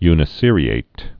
(ynĭ-sîrē-āt, -ĭt)